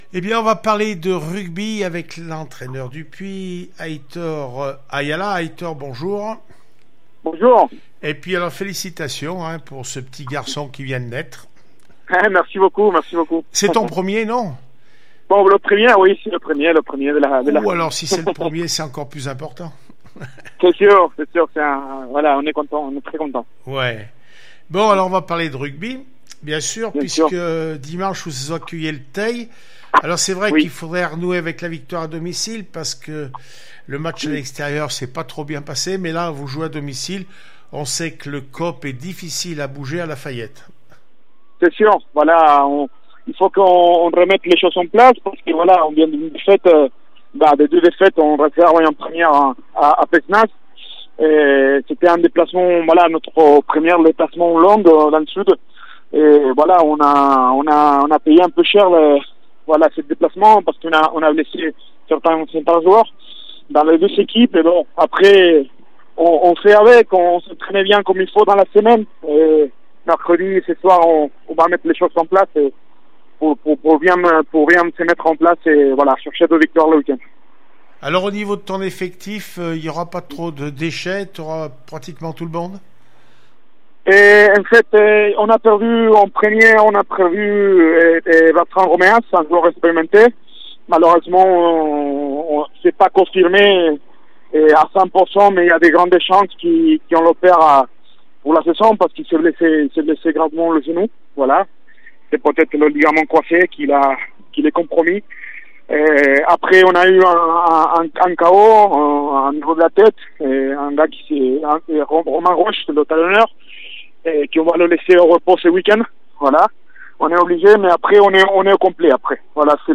10 octobre 2020   1 - Sport, 1 - Vos interviews, 2 - Infos en Bref   No comments